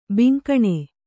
♪ binkaṇe